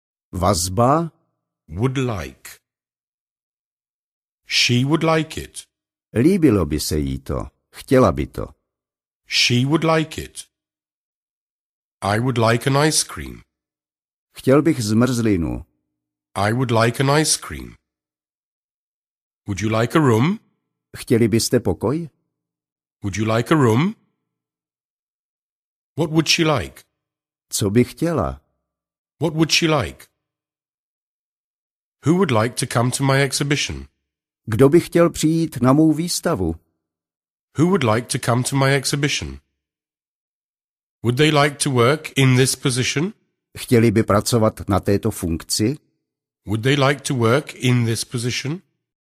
Každou větu uslyšíte anglicky, pak česky a znovu v originálním znění.
Ukázka z knihy
Místo vysvětlování teorie jsme použili gramatiku ve větách namluvených příjemným hlasem rodilého Angličana.